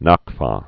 (näk)